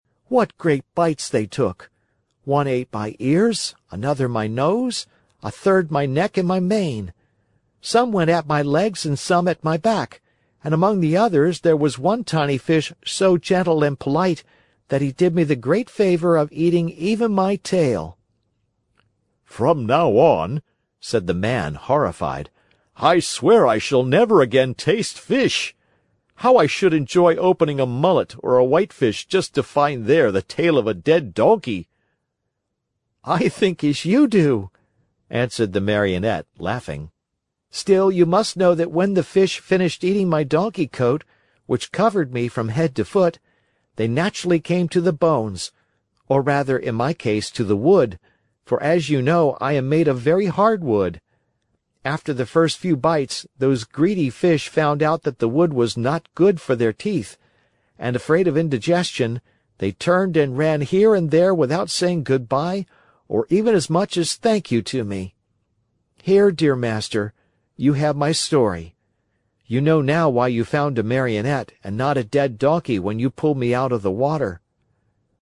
在线英语听力室木偶奇遇记 第142期:匹诺曹落入鱼腹(4)的听力文件下载,《木偶奇遇记》是双语童话故事的有声读物，包含中英字幕以及英语听力MP3,是听故事学英语的极好素材。